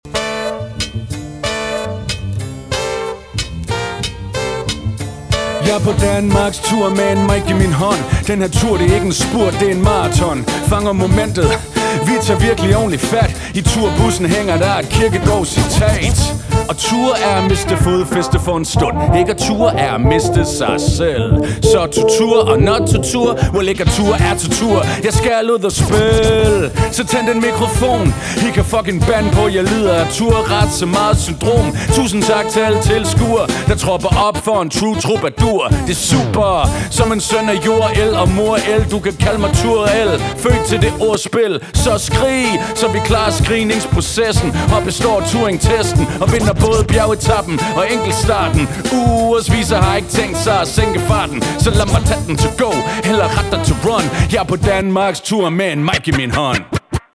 • Hip hop
• Spoken word
Vokal